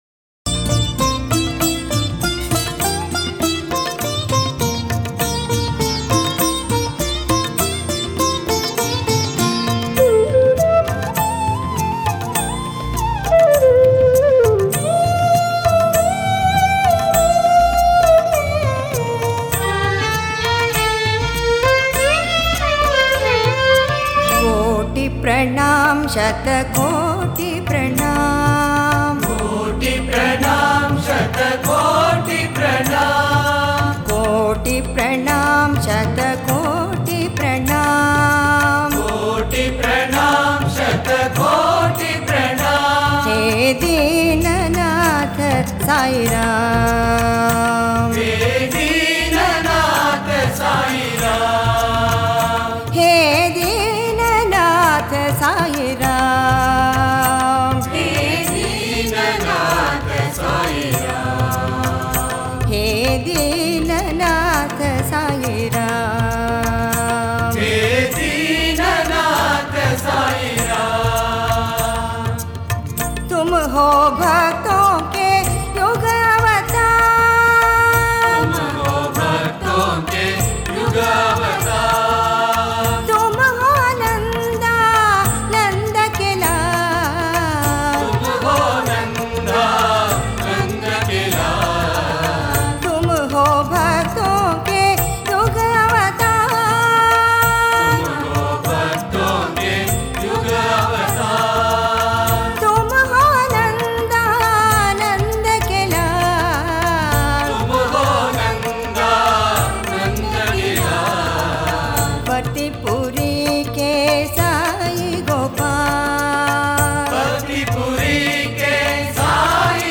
Sai Bhajans